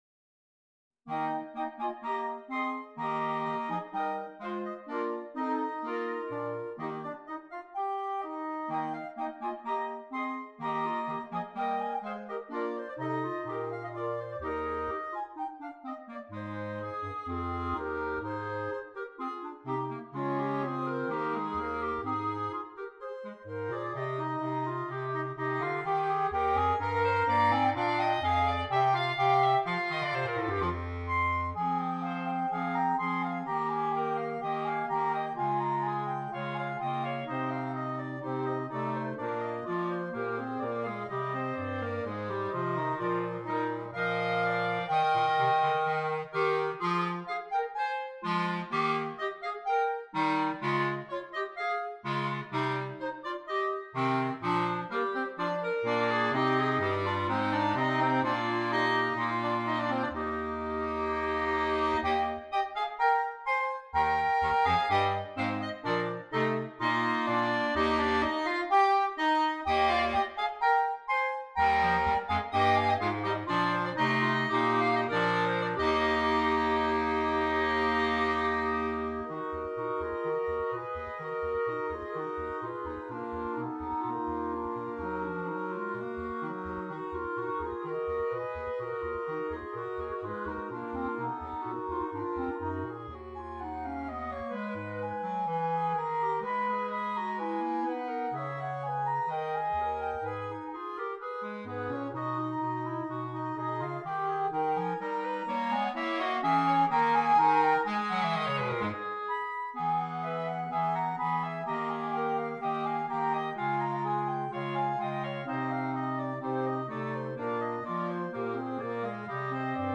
Voicing: Clarinet Quintet